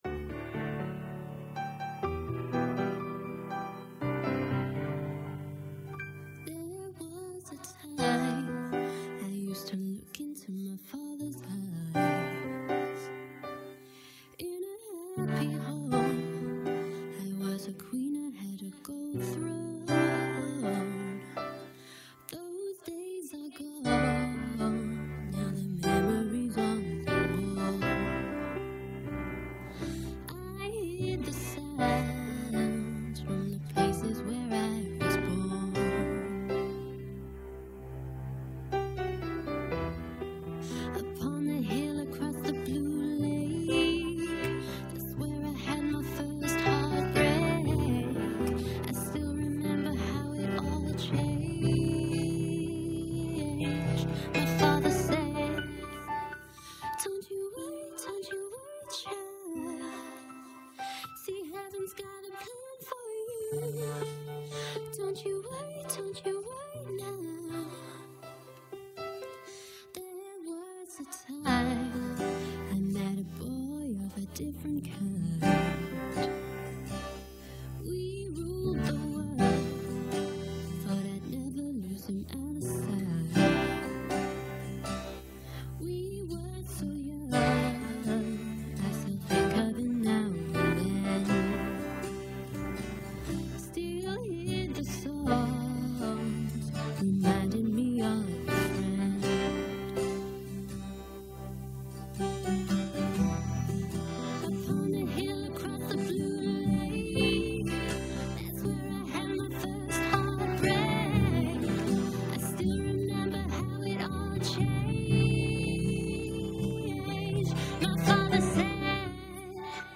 Piano-Instrumental - Kopie.mp3